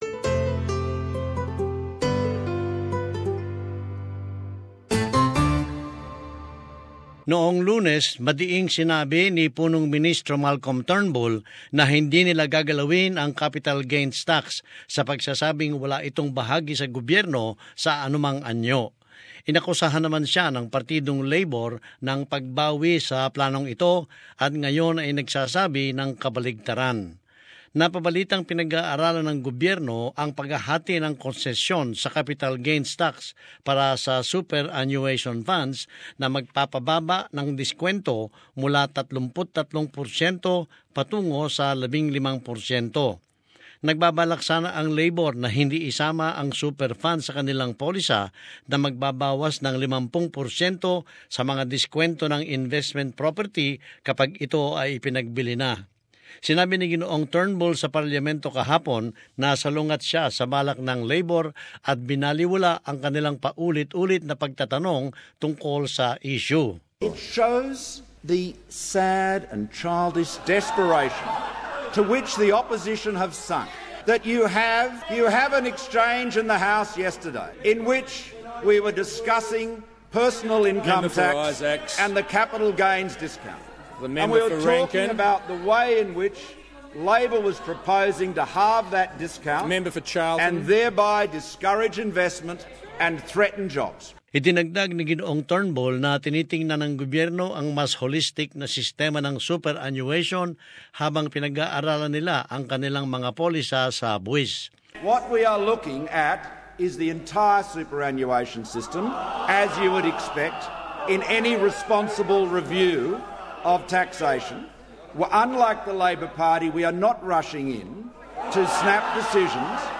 In this report, Labor is critical of Mr Turnbull for what it labels 'misleading statements' about the tax reforms.